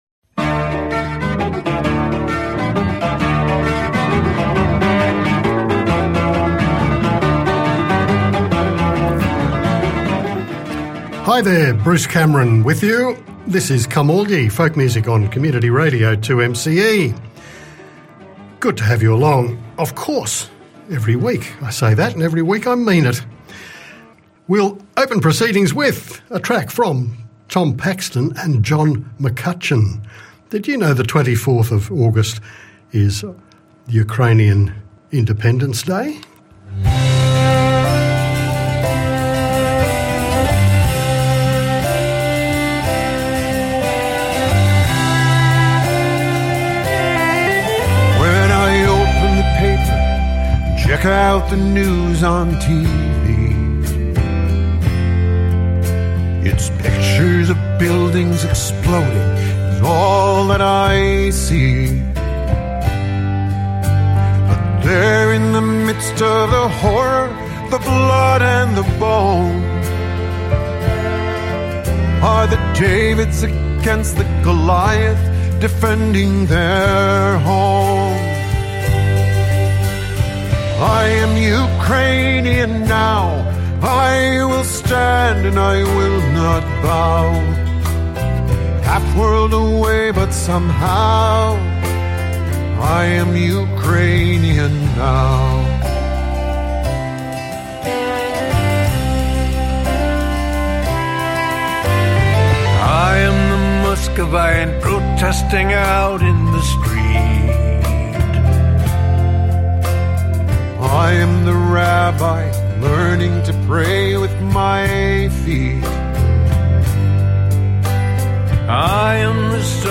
traditional Scottish folk songs